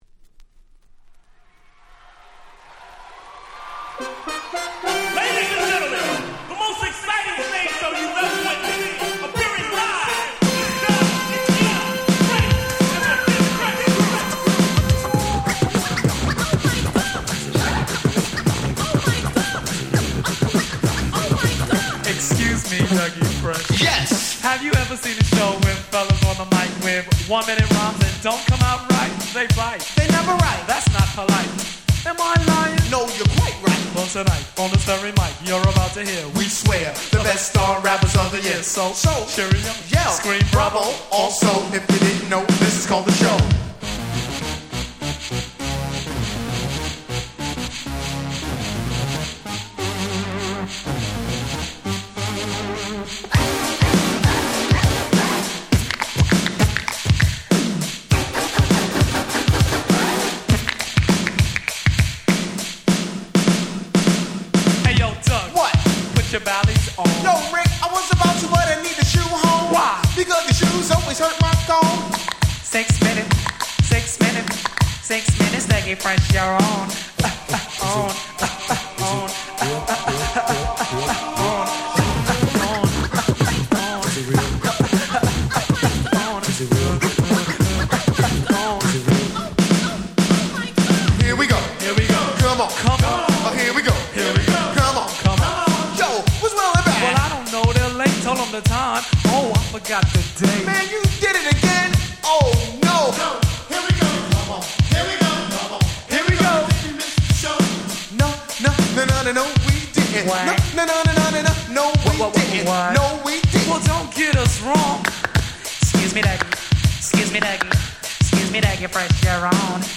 85' Super Hip Hop Classics !!
問答無用のOld School HitにしてHip Hop古典。
ダギーフレッシュ スリックリック 80's オールドスクール Boom Bap ブーンバップ